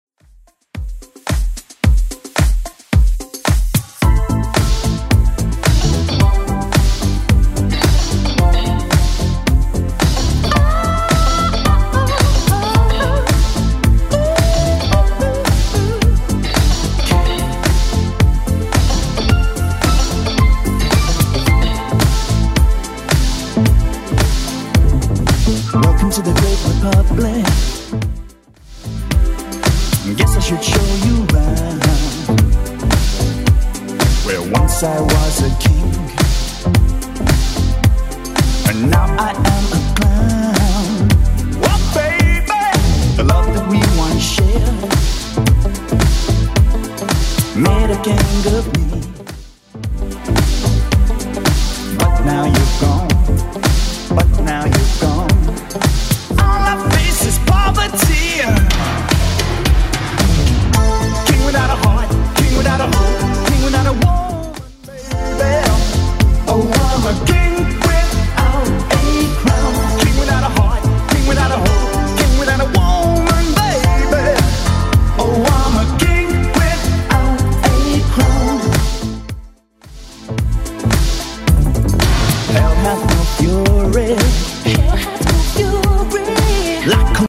Genre: 90's
BPM: 100